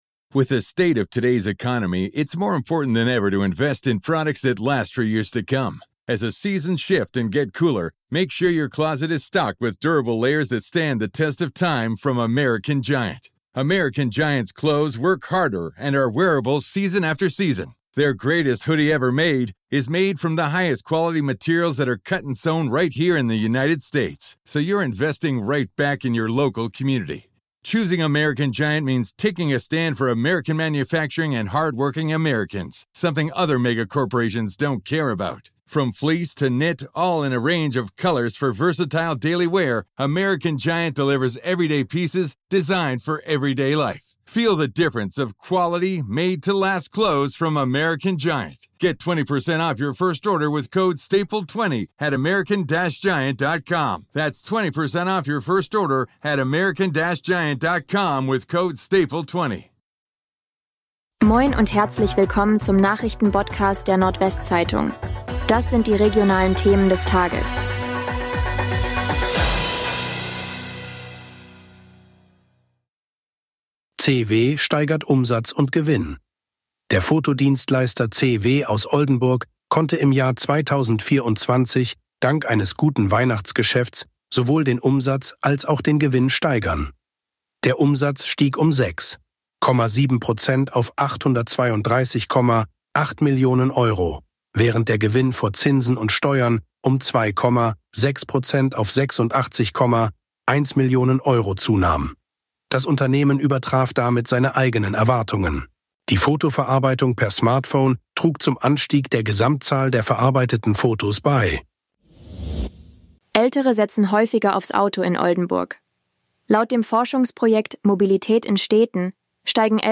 NWZ Nachrichten Botcast – der tägliche News-Podcast aus dem Norden